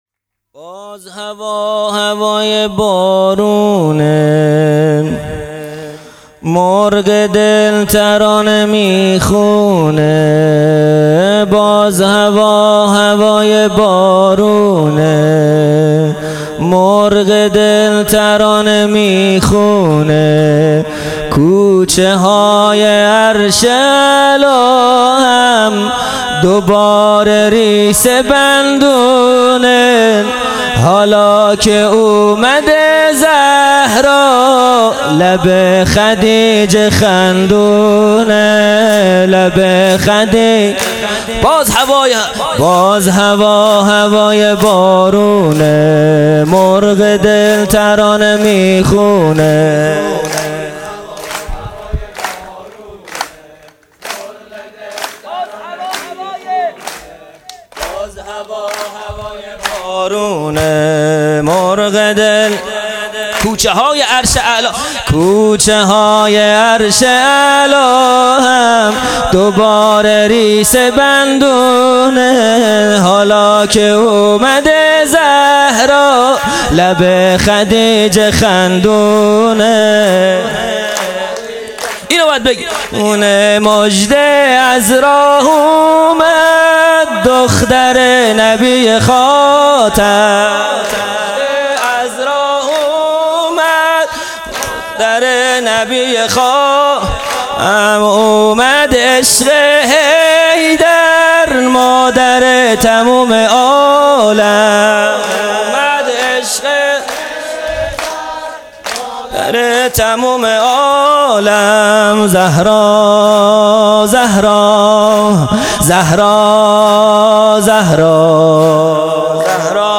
خیمه گاه - هیئت بچه های فاطمه (س) - سرود|پنج شنبه ۱۶ بهمن ۹۹